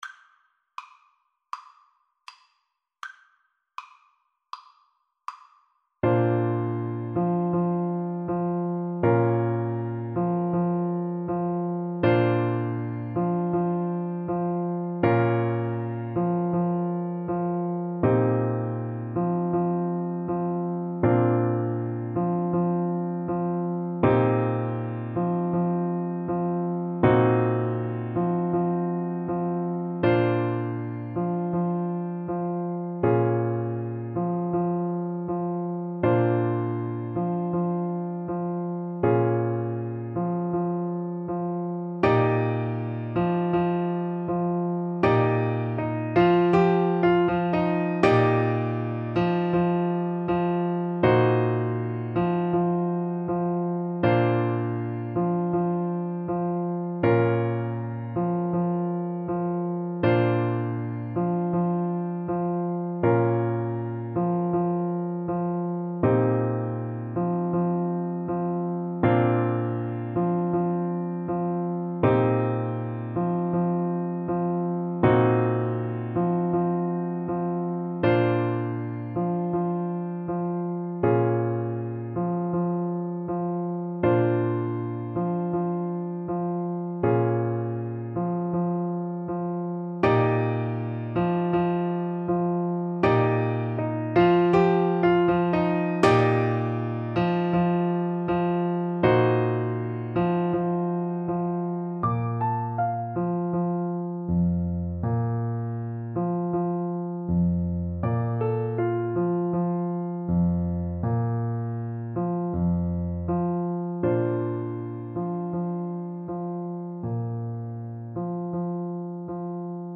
A relaxed melody with a Rumba beat.
Andante